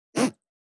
418,ジッパー,チャックの音,洋服関係音,ジー,
ジッパー効果音洋服関係